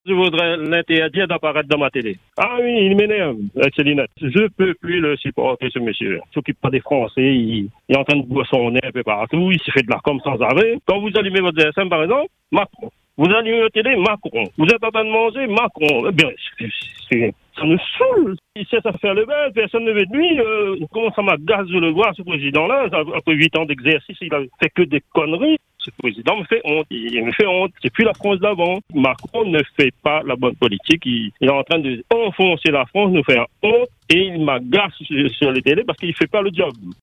Sur notre antenne, un auditeur n’a pas caché son exaspération. Pour lui, cette mise en scène et cette communication desservent la fonction présidentielle. Il parle d’une image dégradante et dit ressentir une forme de honte pour le pays, estimant que la posture du chef de l’État ne serait pas à la hauteur du rôle qu’il incarne.